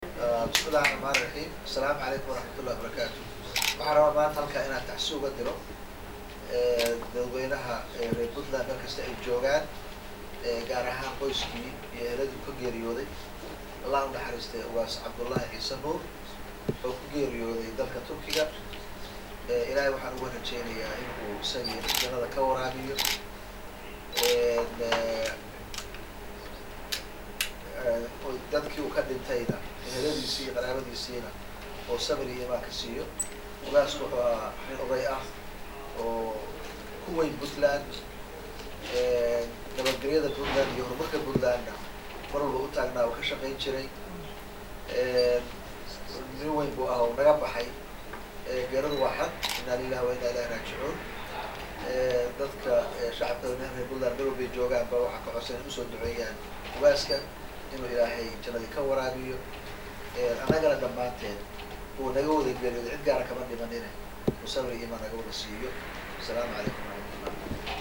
CODKA-MADAXWEYNAHA-23.mp3